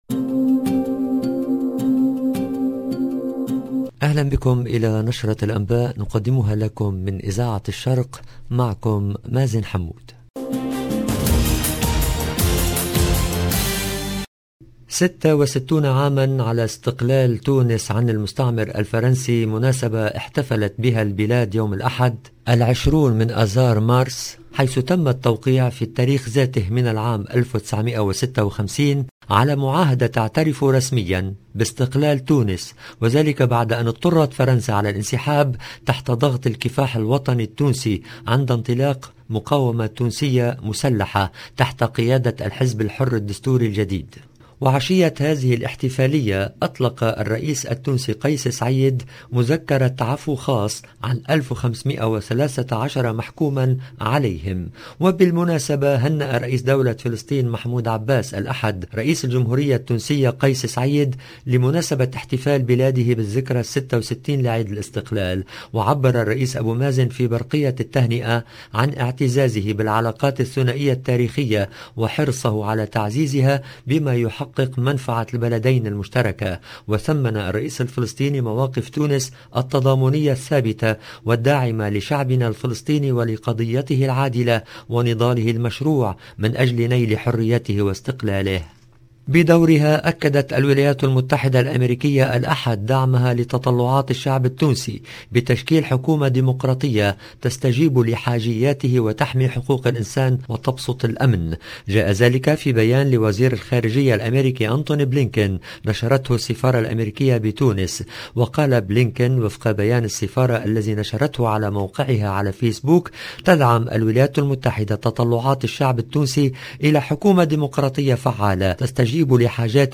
EDITION DU JOURNAL DU SOIR EN LANGUE ARABE DU 20/3/2022